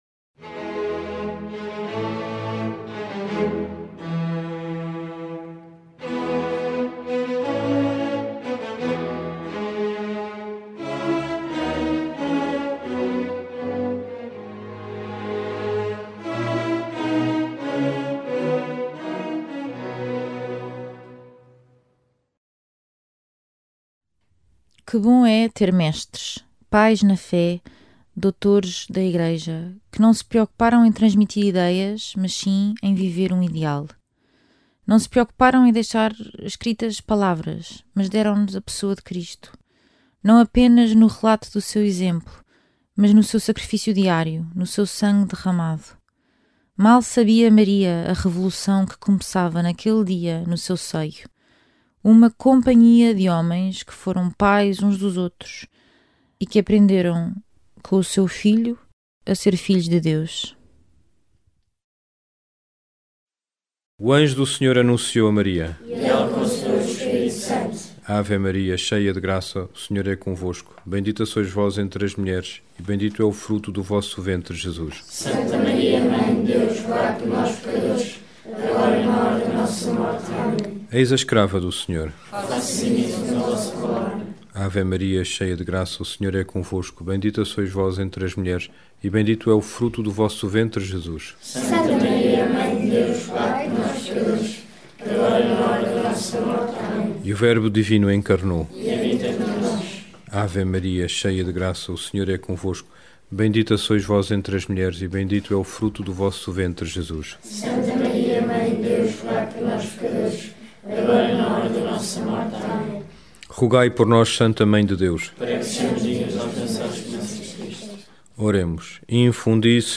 Angelus, Meditações Diárias